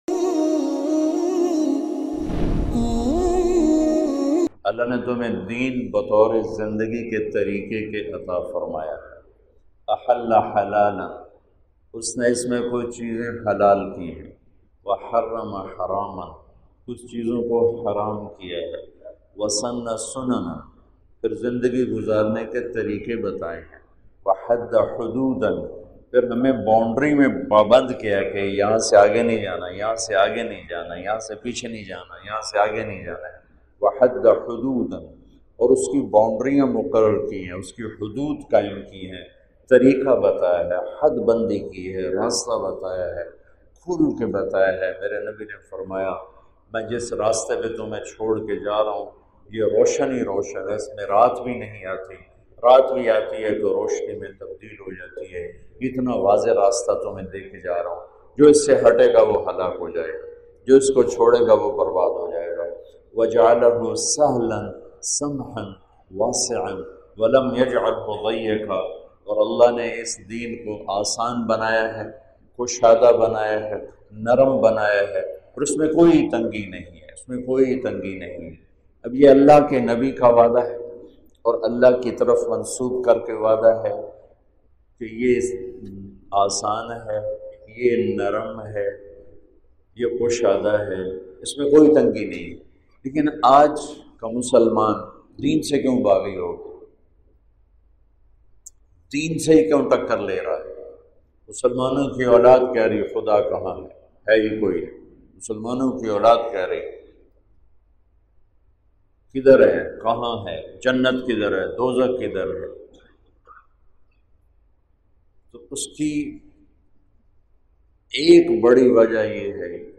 Tariq Jameel, commonly referred to as Maulana Tariq Jameel, is a Pakistani religious and Islamic scholar, preacher, and public speaker from Tulamba near Mian Channu in Khanewal, Punjab in Faisalabad, Pakistan.
Tariq jameel bayan mp3, Tariq jameel bayan audio, Tariq jameel bayan download, Bayan mp3, Bayan audio, Download bayan mp3, free mp3 Tariq jameel , Online bayan tariq jameel,Maulana tariq jameel bayan, Maulana tariq jameel video